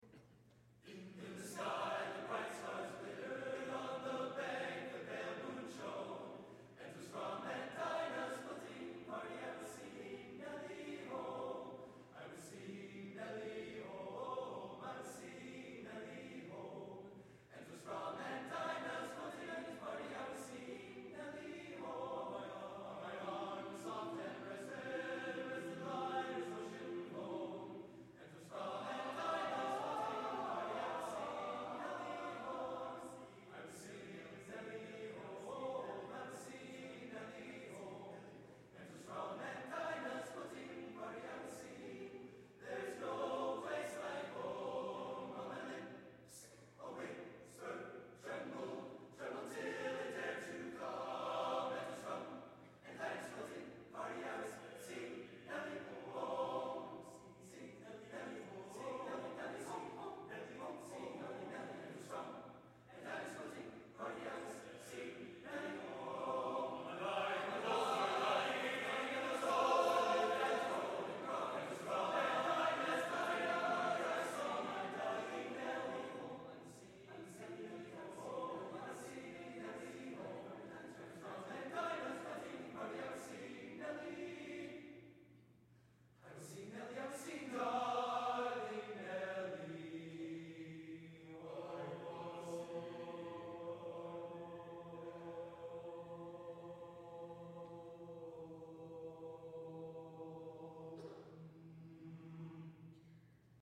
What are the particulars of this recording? Collection: Bonita Springs, Florida Tour, 2008 Location: Bonita Springs, Florida